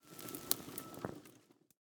Minecraft Version Minecraft Version 1.21.5 Latest Release | Latest Snapshot 1.21.5 / assets / minecraft / sounds / block / vault / ambient3.ogg Compare With Compare With Latest Release | Latest Snapshot
ambient3.ogg